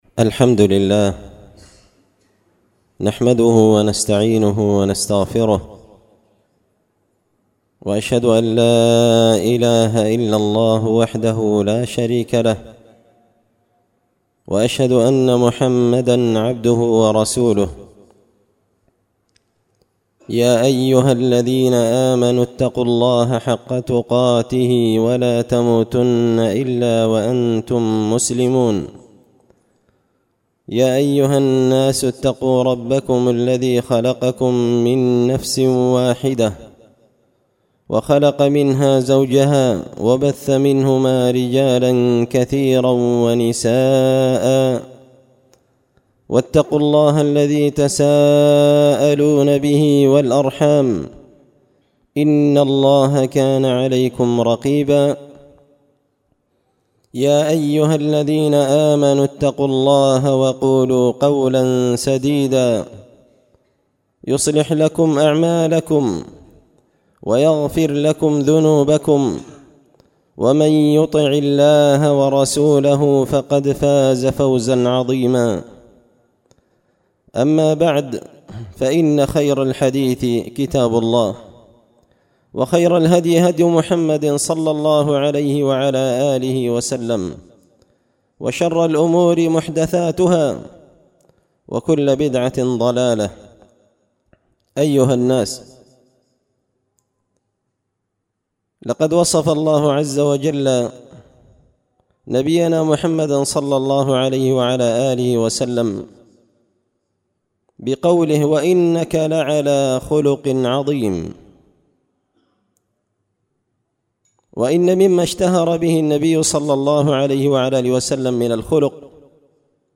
خطبة جمعة بعنوان – قناة الجزيرة القطرية مدرسة مسيلمة العصرية
دار الحديث بمسجد الفرقان ـ قشن ـ المهرة ـ اليمن